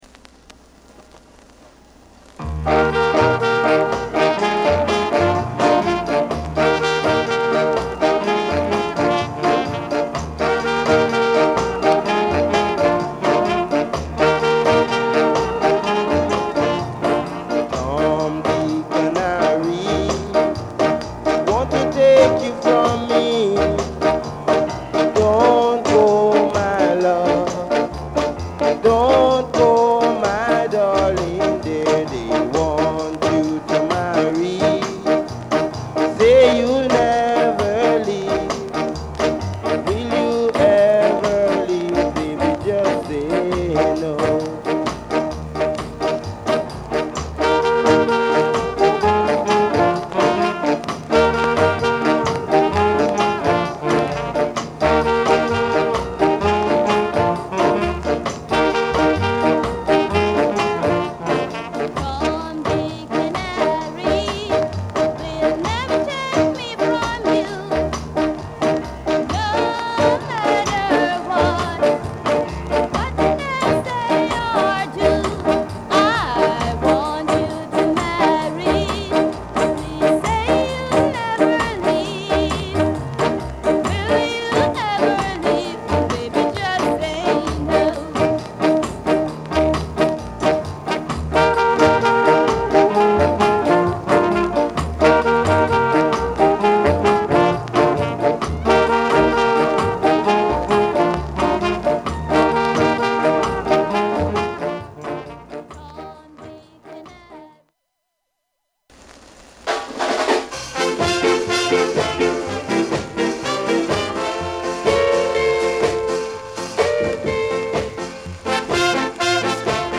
Genre: Ska
軽快なリズムと掛け合いのヴォーカルが持ち味。